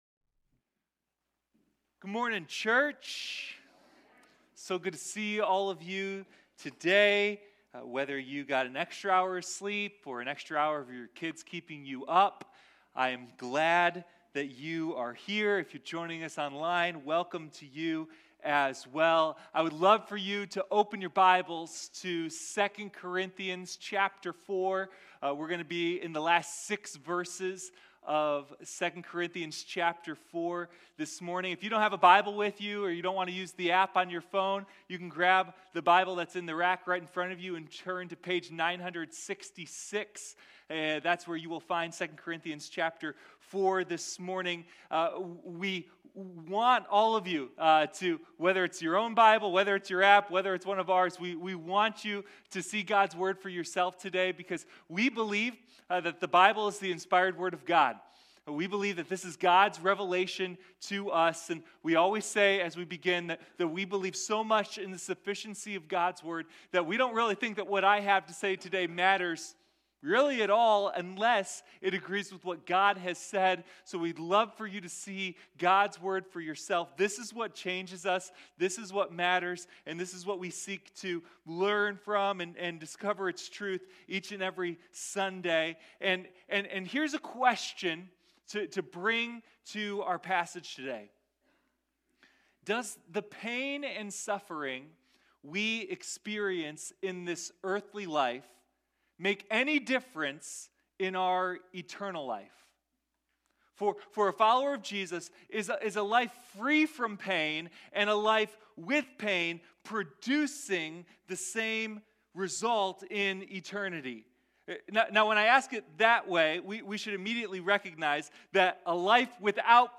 Sunday Morning God's Power in our weakness: 2 Corinthians